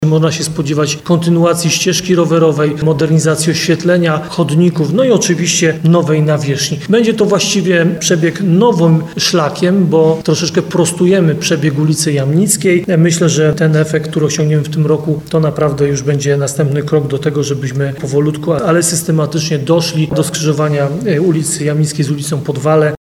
Jak mówi prezydent Nowego Sącza Ludomir Handzel, będzie to kompleksowy remont wraz z przebudową wodociągu i nie tylko.